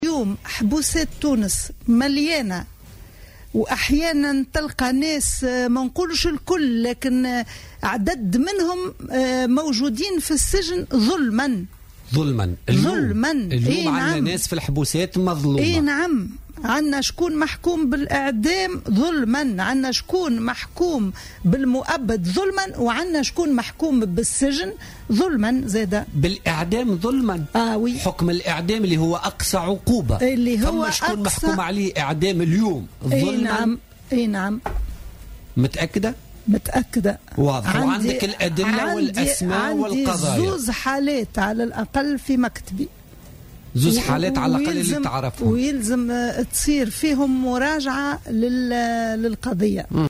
وأضافت النصراوي ضيفة برنامج "بوليتيكا" اليوم الأربعاء أن لديها حالتين على الأقل في مكتبها، داعية إلى ضرورة مراجعة هذه القضايا.